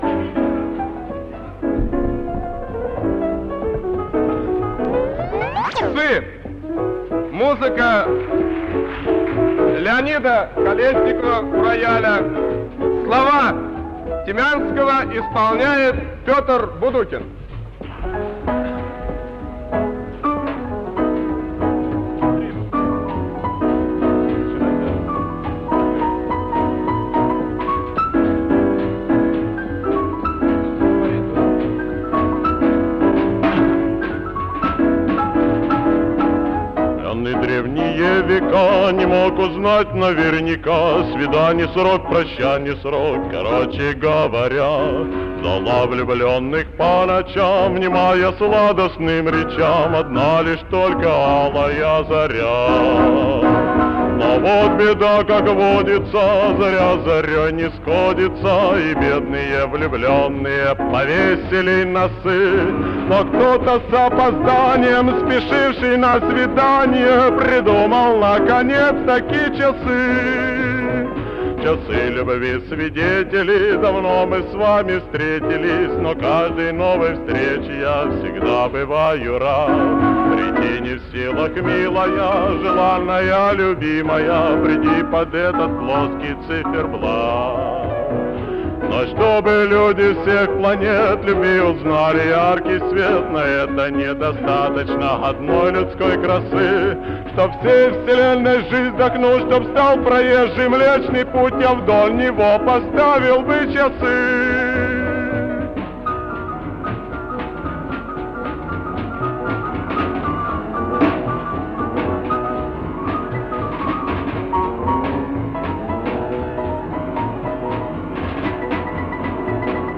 ВТОРОЙ МОСКОВСКИЙ ВЕЧЕР-КОНКУРС СТУДЕНЧЕСКОЙ ПЕСНИ
фортепьяно